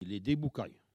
Enquête Arexcpo en Vendée-Lucus
Catégorie Locution